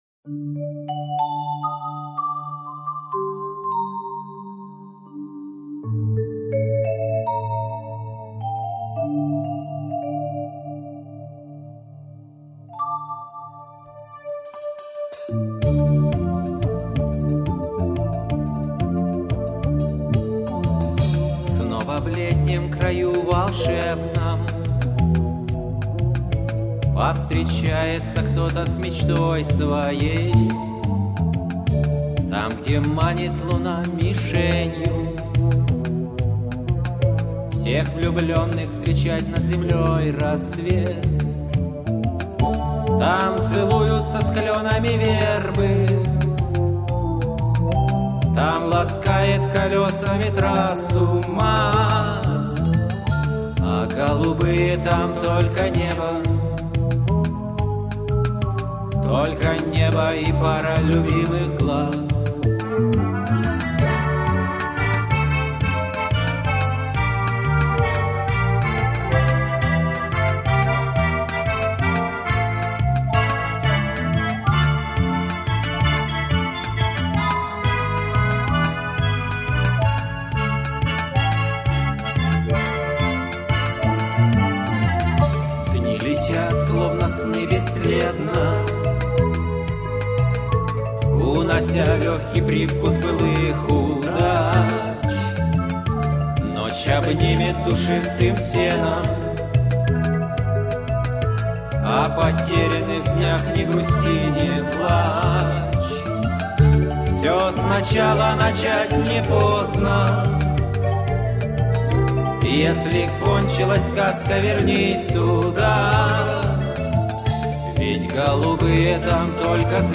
жутко доставила мне эта песенка, она такая милая, даже нежная))))))))